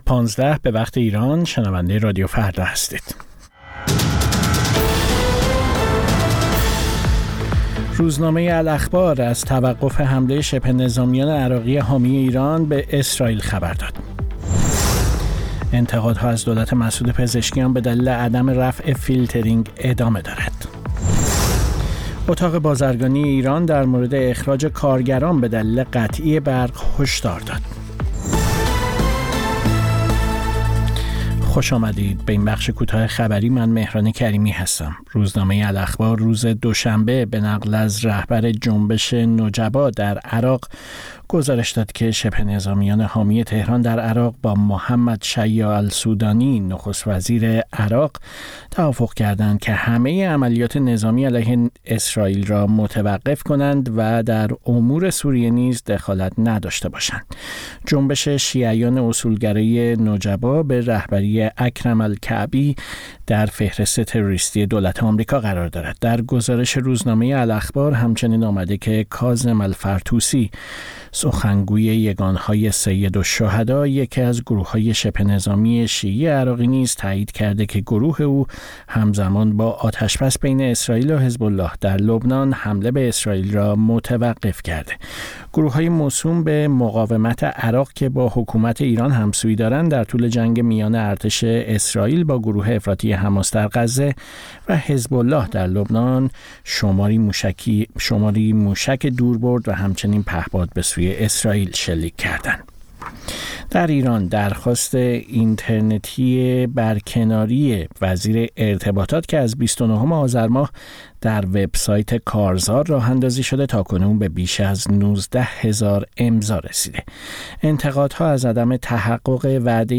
سرخط خبرها ۱۵:۰۰